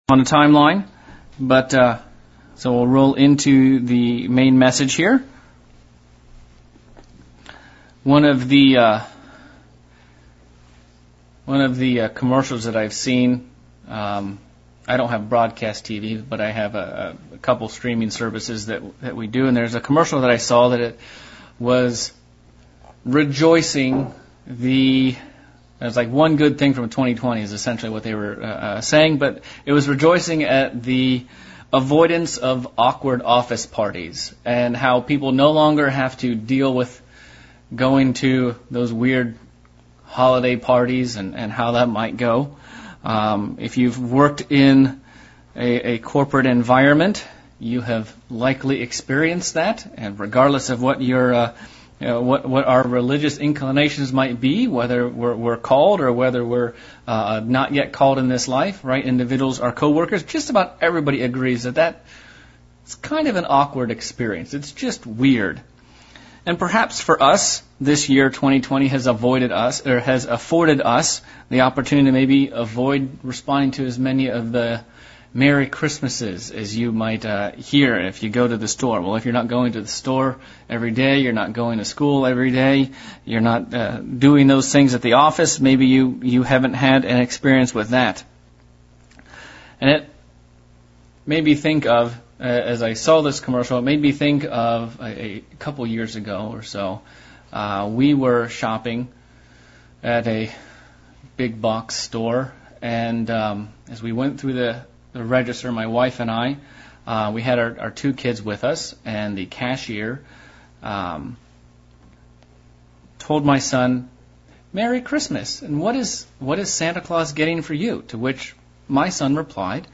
Sermon looking at the subject of what should we be ready to give an answer for and how we can prepare.